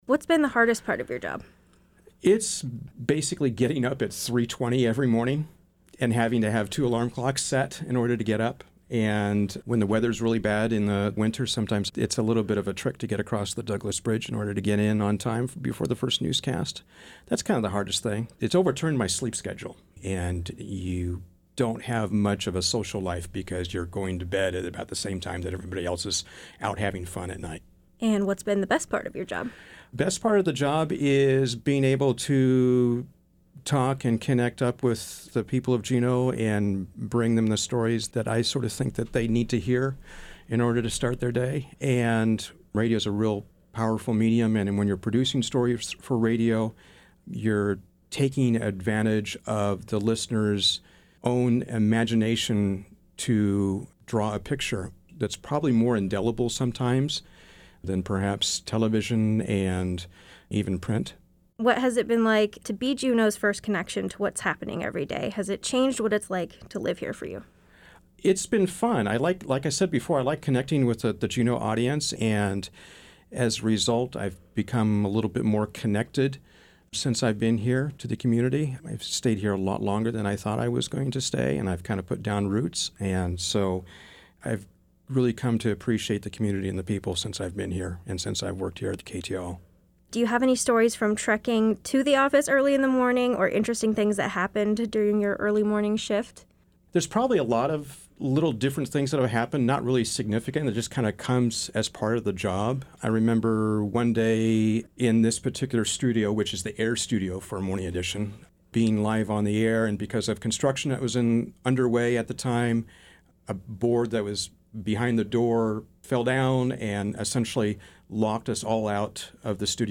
Read a transcript of the conversation with minor edits for clarity.